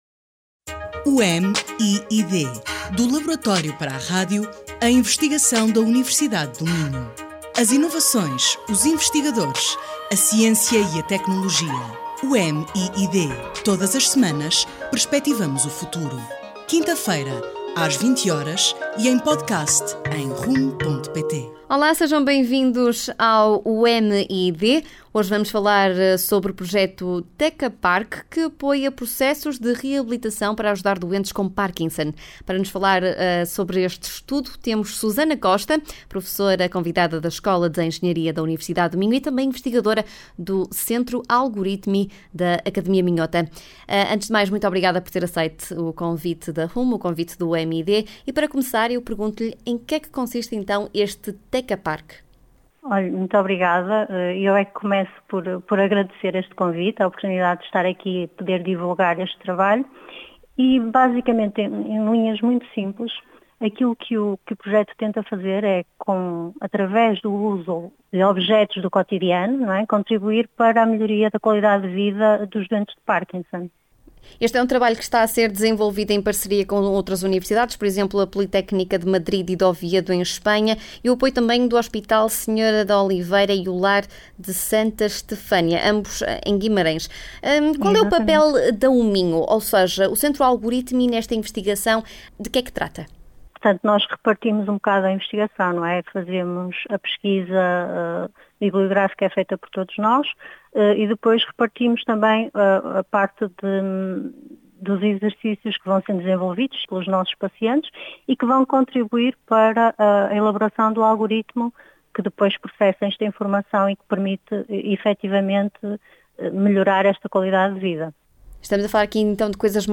Teca-Park – entrevista